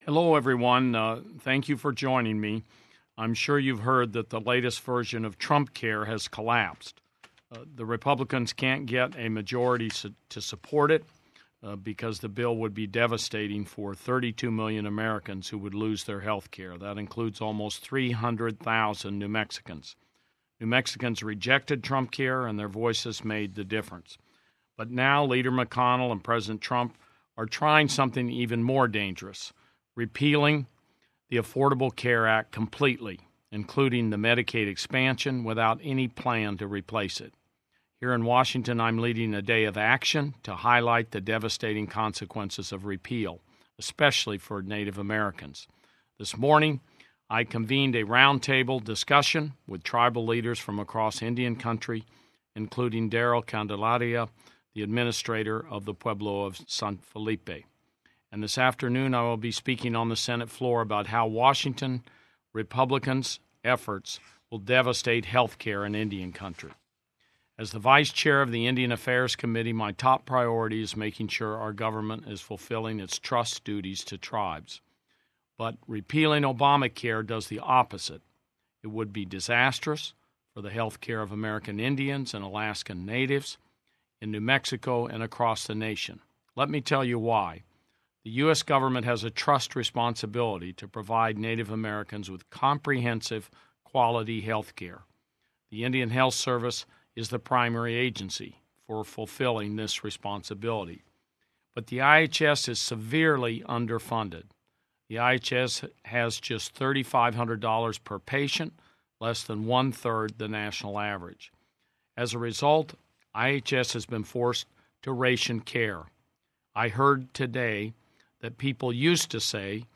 WASHINGTON — Today, on a conference call with New Mexico reporters, U.S. Senator Tom Udall, vice chairman of the Senate Committee on Indian Affairs, outlined the devastating impacts that repealing the Affordable Care Act would have on Tribal communities in New Mexico and throughout the nation.